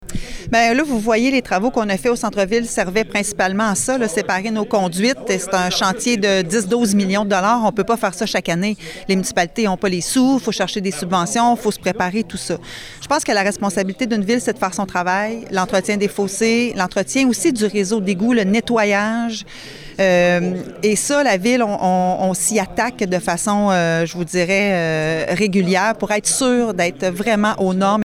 La mairesse de Nicolet, Geneviève Dubois, a plutôt insisté sur l’aspect de sensibilisation auprès des citoyens, tout en mentionnant que Nicolet effectuait régulièrement des travaux à ce chapitre.